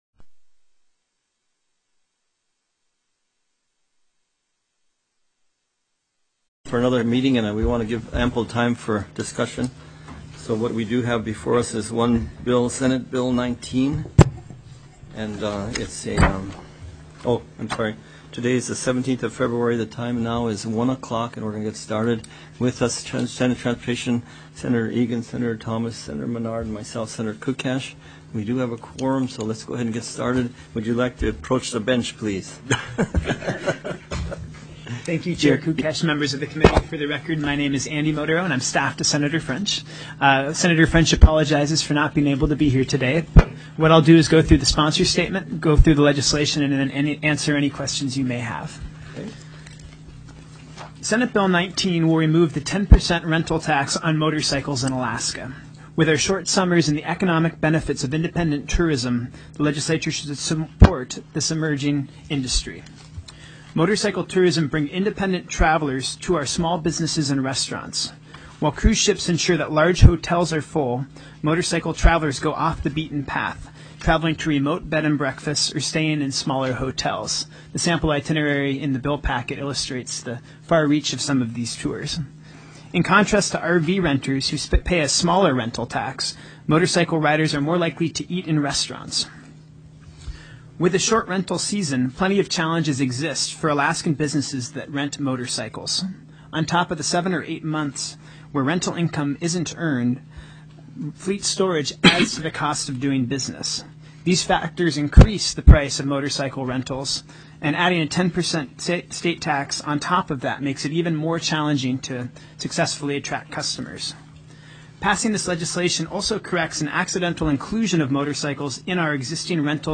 SB 19 PASSENGER VEHICLE RENTAL TAX TELECONFERENCED